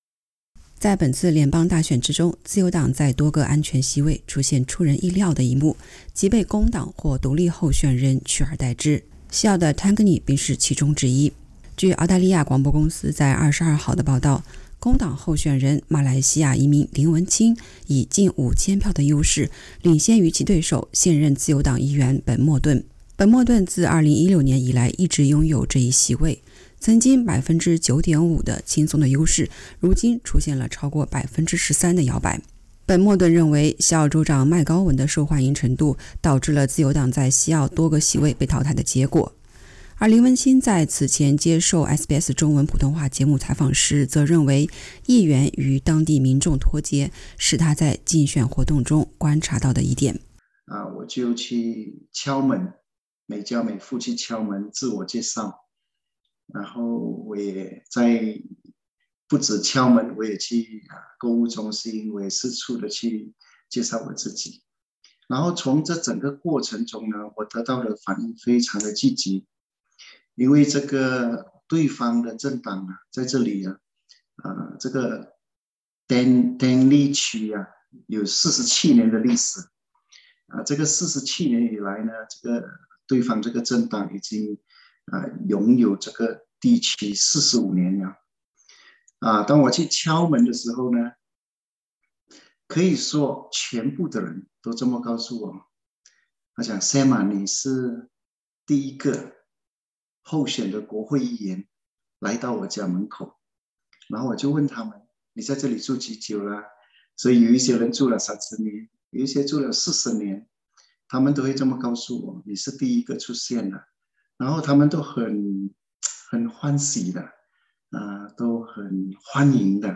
【专访】林文清：从会说10种语言的华裔警官到国会议员